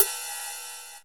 D2 RIDE-11.wav